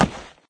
woodgrass2.ogg